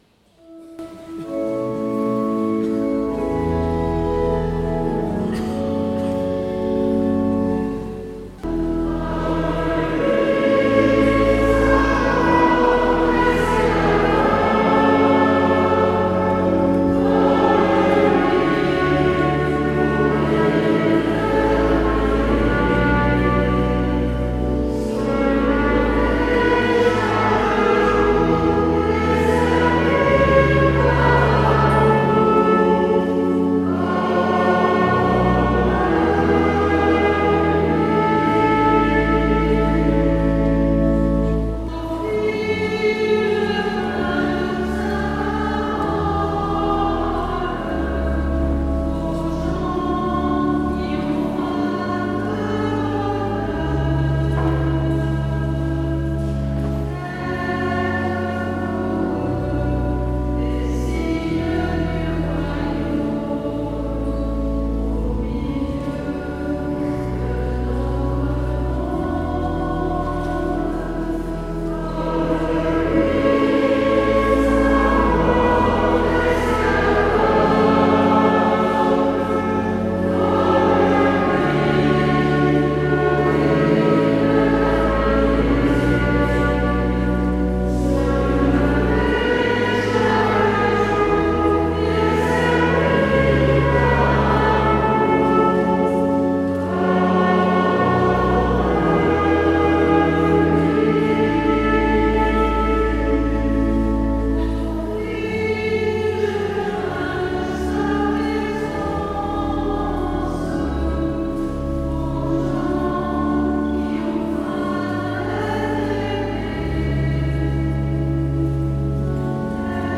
Comme lui - Chorale Paroissiale du Pôle Missionnaire de Fontainebleau